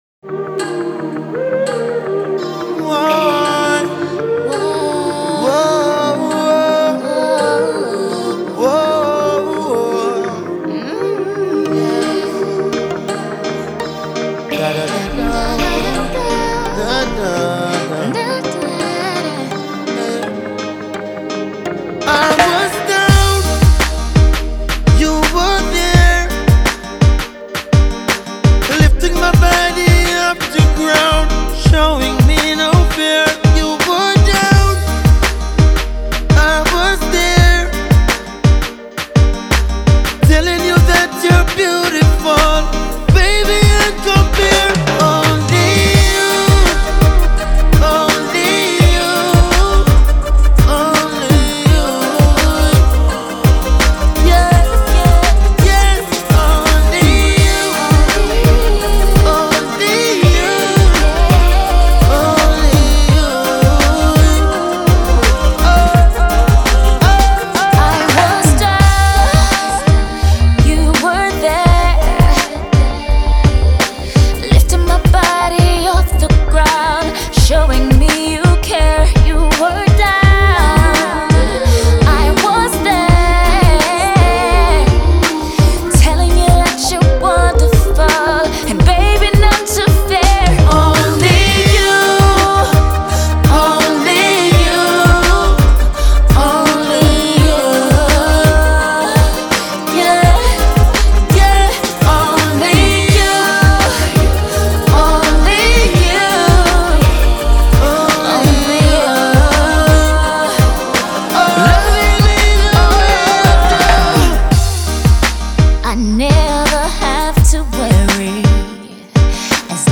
En el mundo del reggae, la voz ronca
La estrella del reggae regresa con una nueva canción de amor